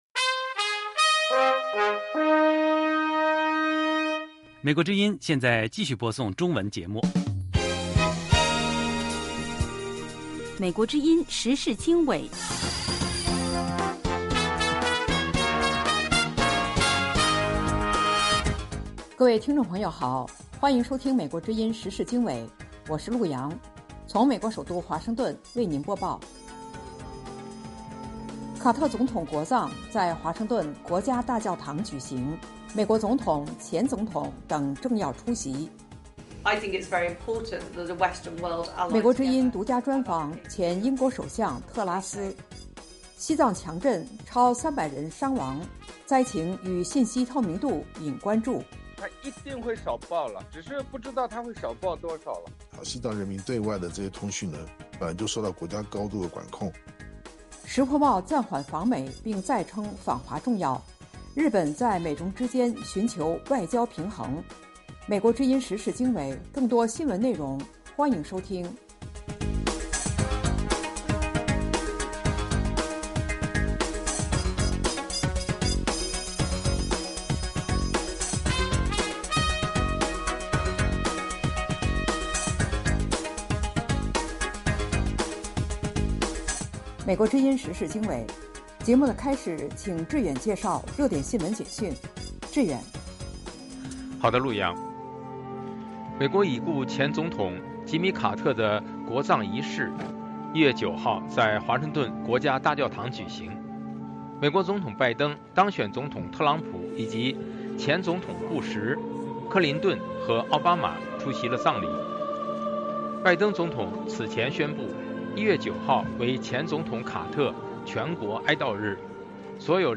【时事经纬】（2025年1月10日） 卡特总统国葬在华盛顿国家大教堂举行；VOA独家专访前英国首相特拉斯；西藏强震逾300人伤亡 灾情与信息透明度引关注；达赖喇嘛为西藏地震灾民主持祈祷法会；石破茂暂缓访美并再称访华重要, 日本在美中之间寻求外交平衡